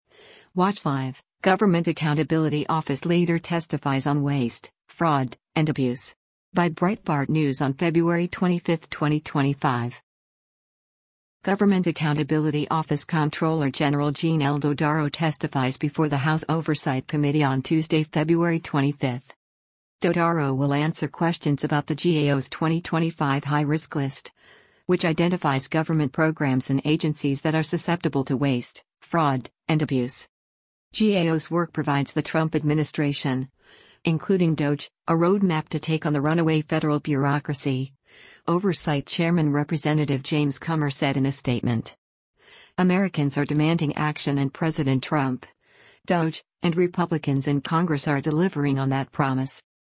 Government Accountability Office Comptroller General Gene L. Dodaro testifies before the House Oversight Committee on Tuesday, February 25.